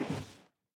paddle_land5.ogg